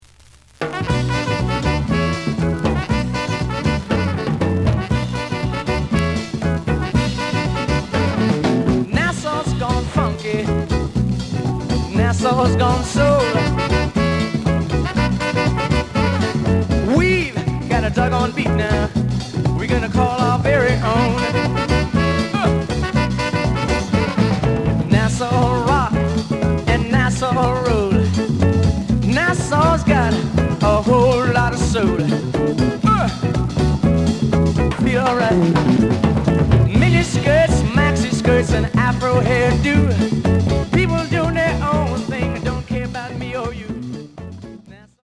The audio sample is recorded from the actual item.
●Genre: Funk, 70's Funk
Slight noise on both sides.)